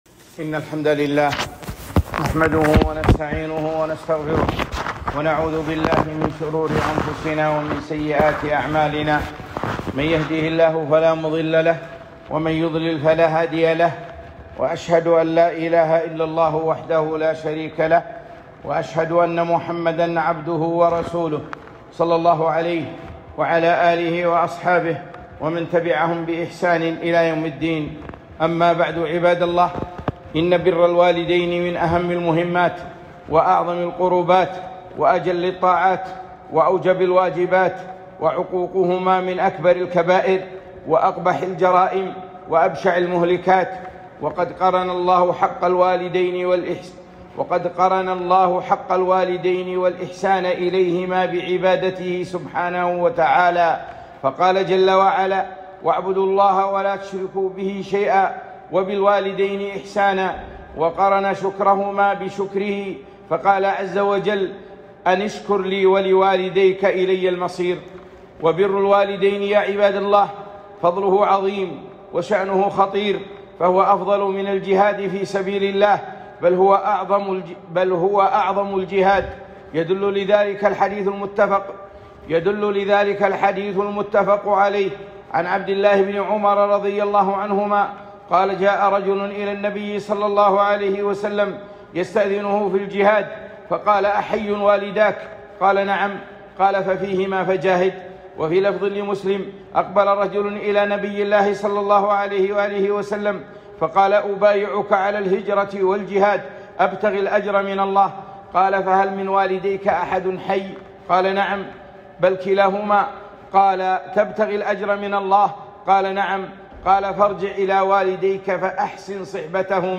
خطبة - بر الوالدين